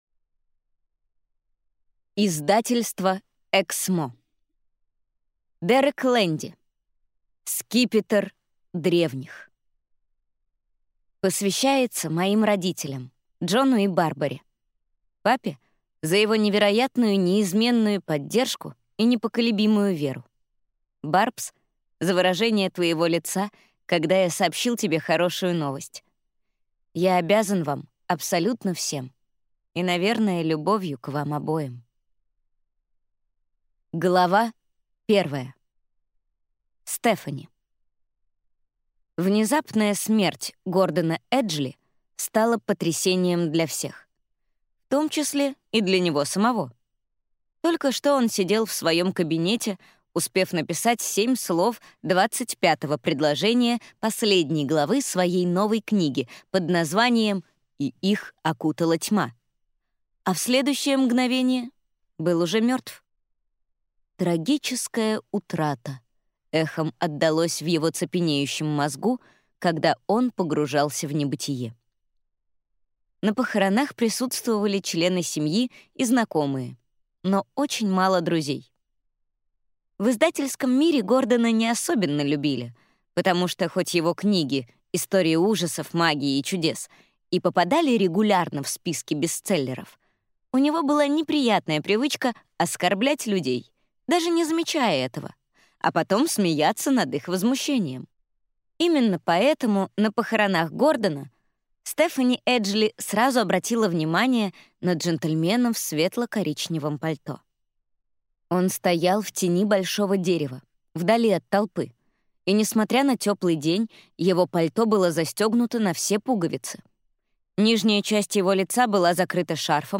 Аудиокнига Скипетр Древних | Библиотека аудиокниг